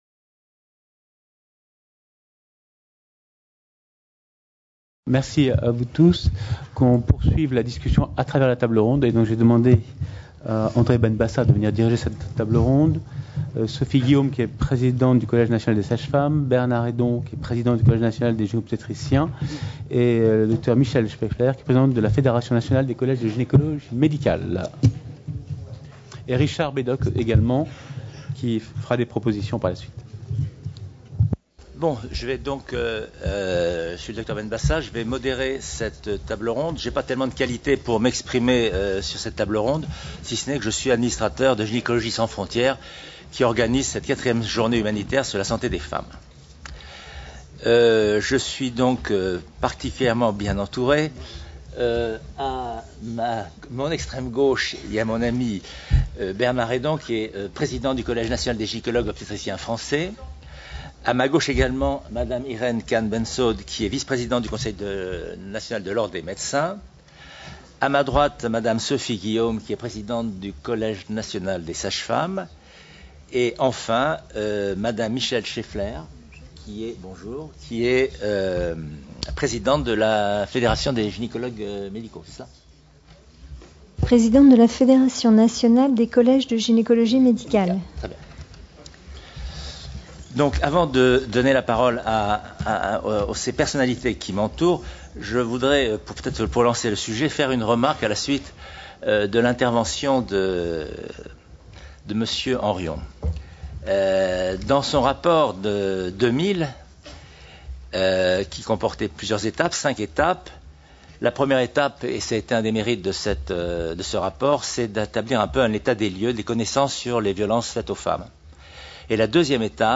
4ème Journée Humanitaire sur la Santé des Femmes, organisée par Gynécologie Sans Frontières, le 29 novembre 2013, au Palais du Luxembourg (Paris). Table ronde : En France, le soigant est-il apte à reconnaître et prendre en charge les femmes victimes de violences au sein et en dehors des familles ?